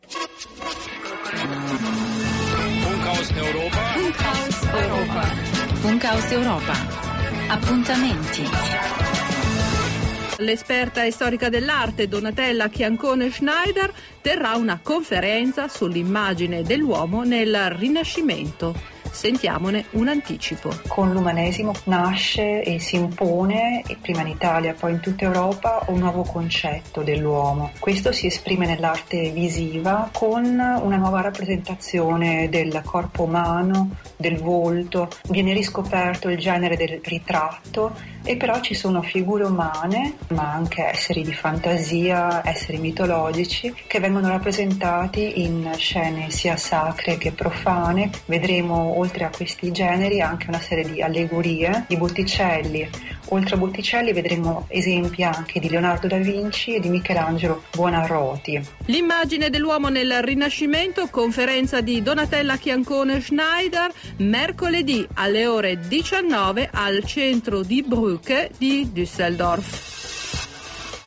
AUDIO: RADIOBEITRÄGE